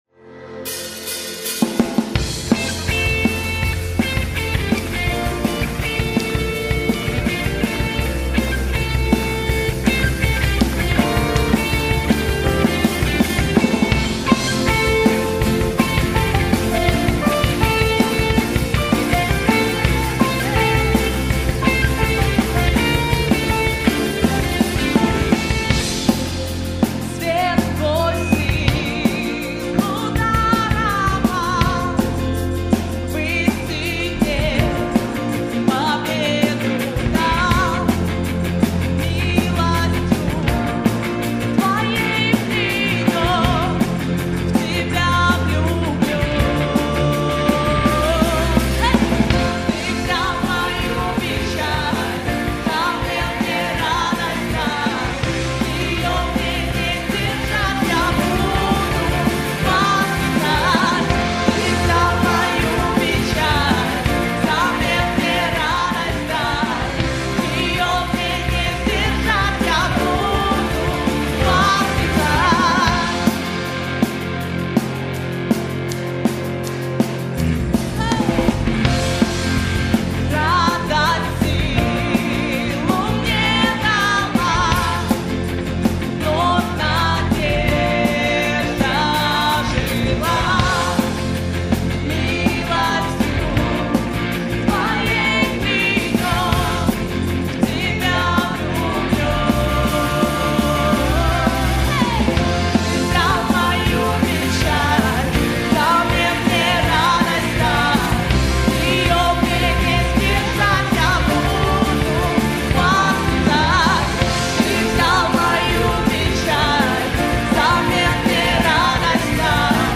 2690 просмотров 1891 прослушиваний 223 скачивания BPM: 163